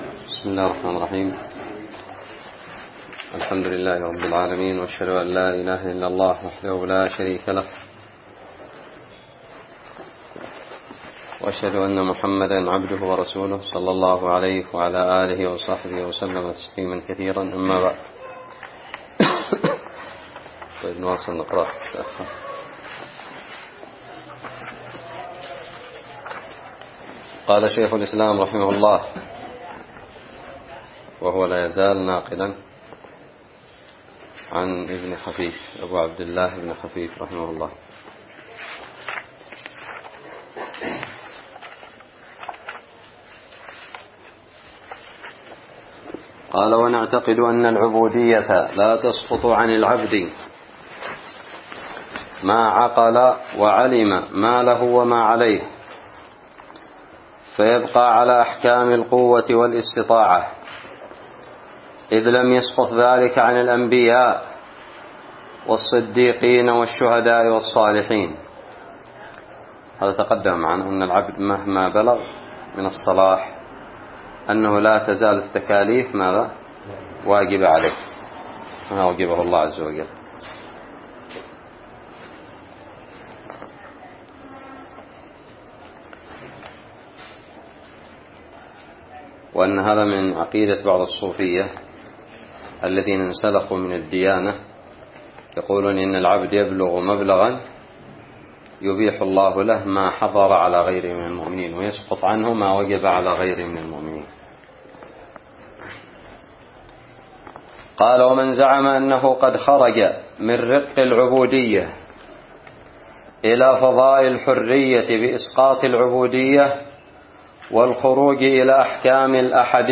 ألقيت بدار الحديث للعلوم الشرعية بالضالع